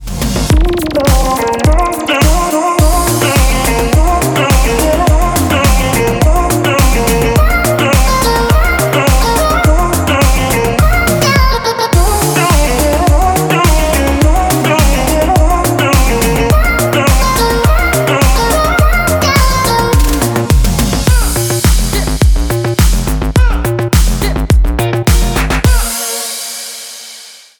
• Качество: 128, Stereo
заводные
Electronic
house
Восхитительный, заводной, берущий за душу рингтон!